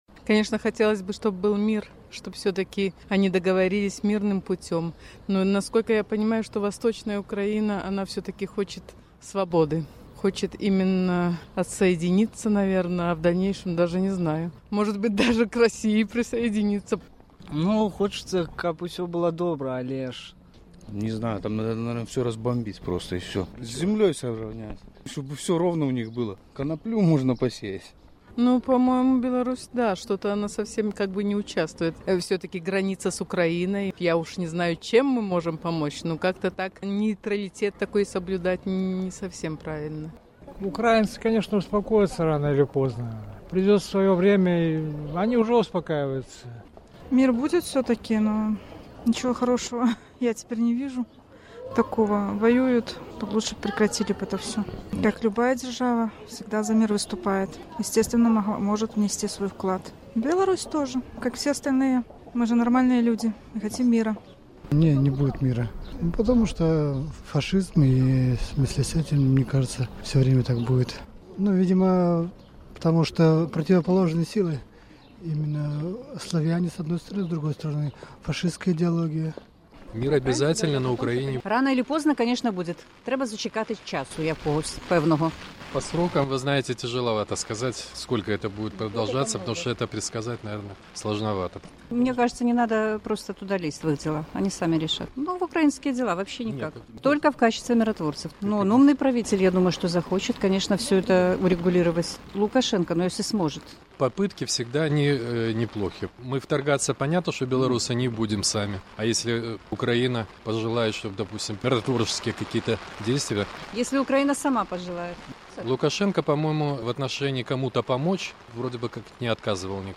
Чым, на вашу думку, скончыцца супрацьстаяньне на ўсходзе Украіны? Якім чынам Беларусь магла б спрыяць урэгуляваньню гэтага канфлікту? Апытаньне ў Горадні.